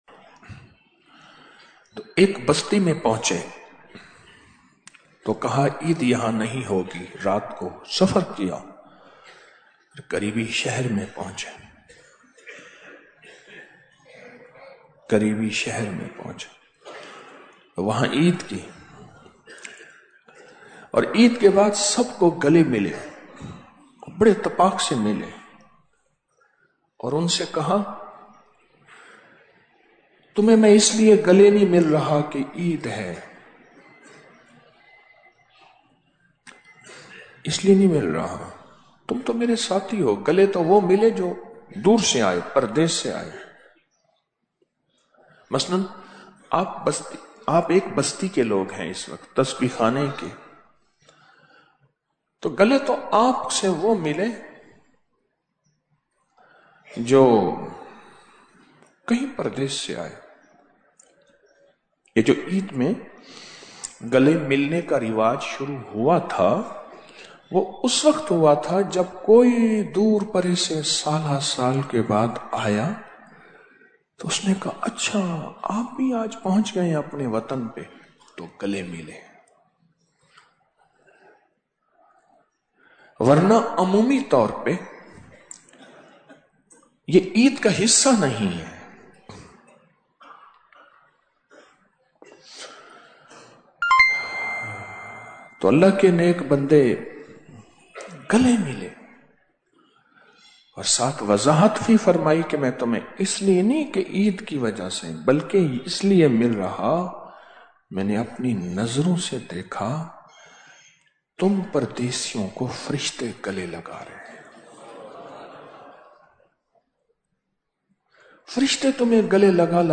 Audio Speech - Eid ul Fitr 2025 Mehfil | 31 Mar 2025